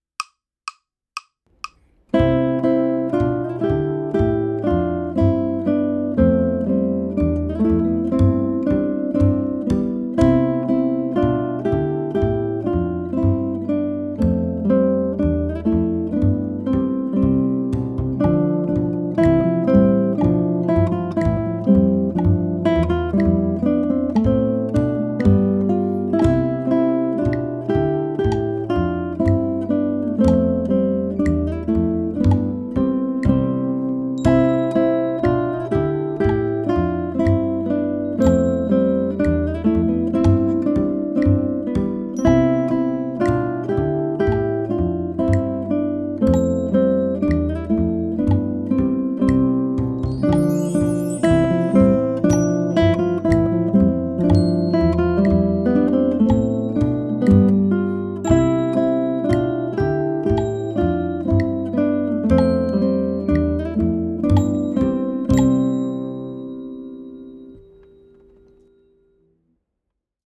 Ode to Joy is arranged for three guitars: guitar 1 (melody), guitar 2 (harmony) and guitar 3 (bass).
Ode_to_Joy_Guitar_trio.mp3